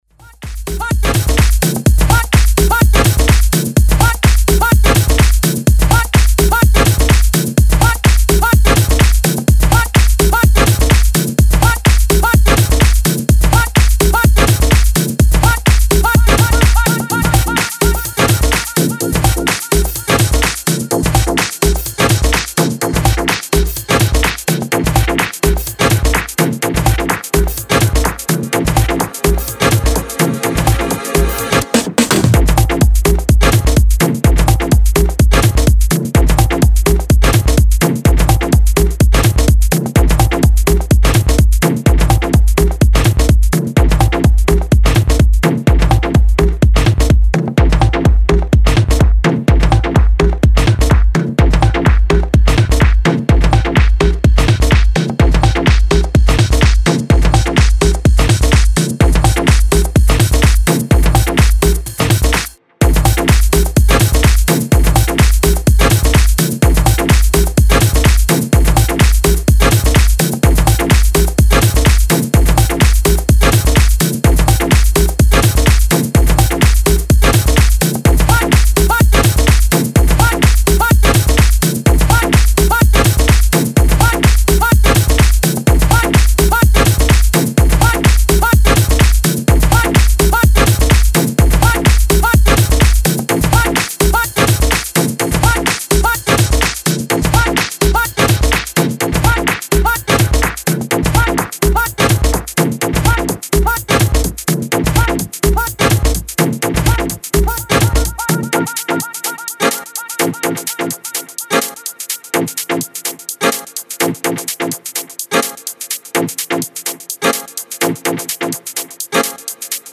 Style: House